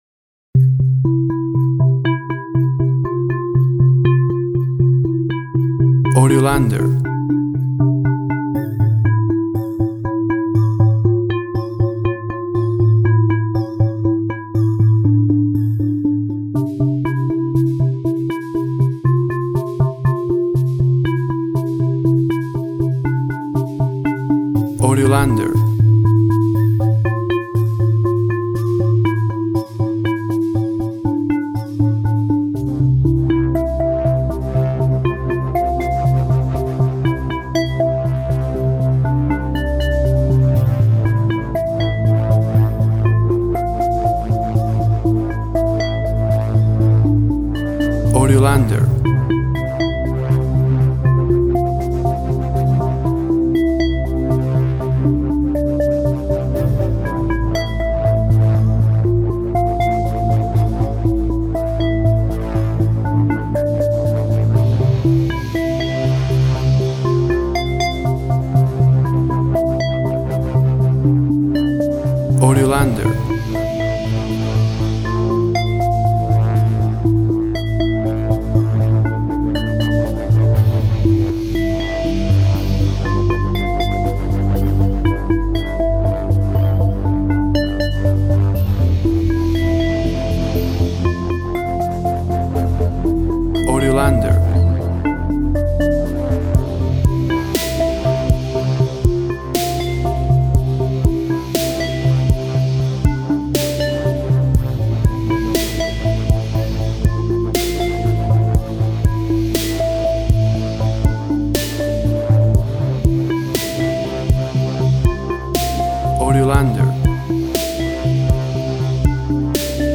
Downtempo Synth influenced track. Romantic feel.
Tempo (BPM) 60